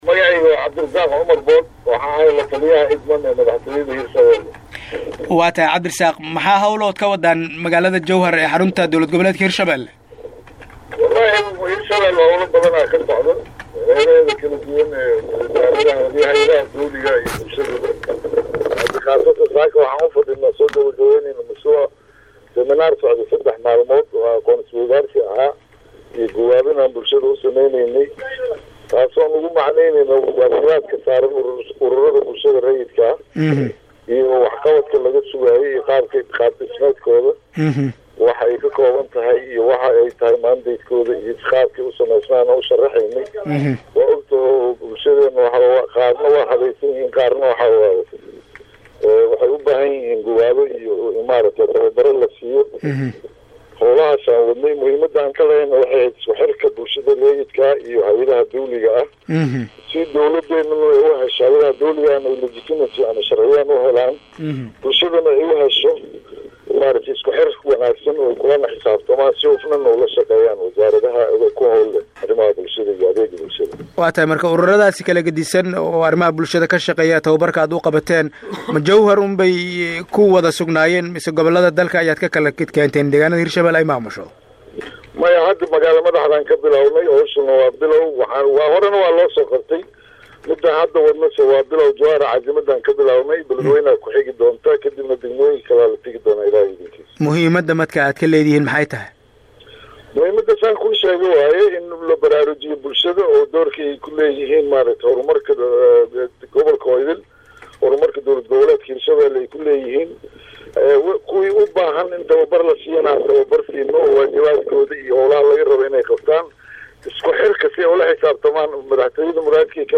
Halkaan Hoose ka Dhageyso Wareysiga